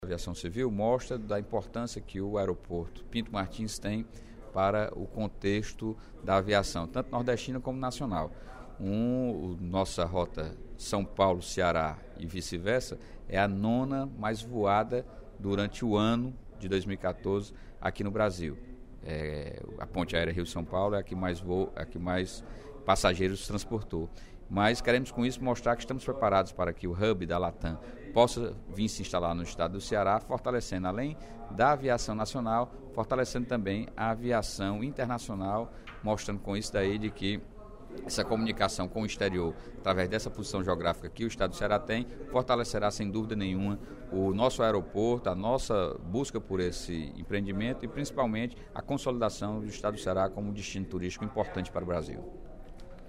O deputado Sérgio Aguiar (Pros) comentou, durante o primeiro expediente da sessão plenária desta sexta-feira (23/10), o estudo “O Brasil que Voa – Perfil dos Passageiros, Aeroportos e Rotas do Brasil”, divulgado nesta quinta-feira (22/10), pela Secretaria de Aviação Civil (SAC).